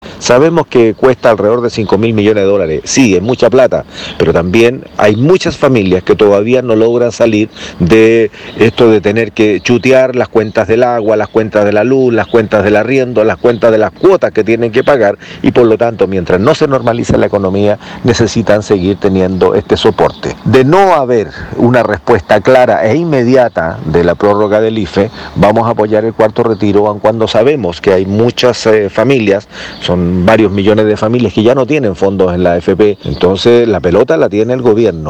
El diputado Iván Flores señaló que una vez más el Gobierno llega tarde, estando aún a la espera de un eventual proyecto para ampliar hasta diciembre el IFE.